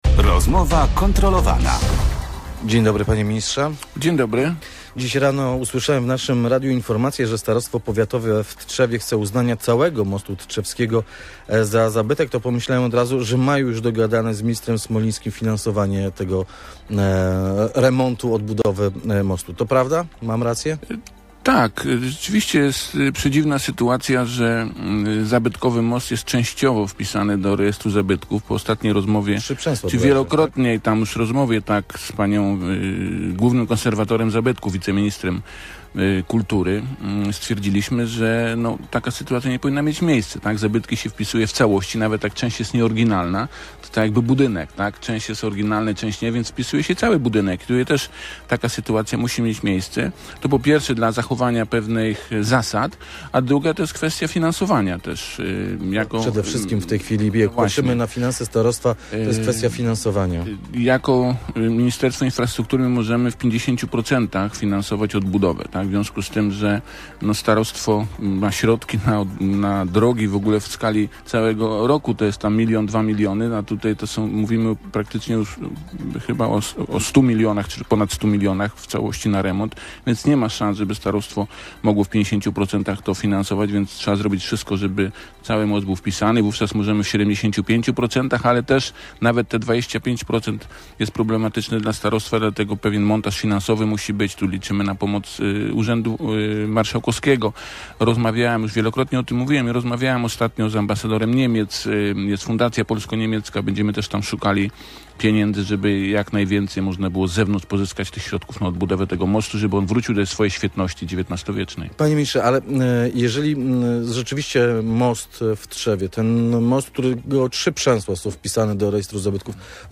Wiceminister infrastruktury i budownictwa Kazimierz Smoliński ocenił w Radiu Gdańsk możliwość wpisania w całości XIX-wiecznego mostu w Tczewie do rejestru zabytków.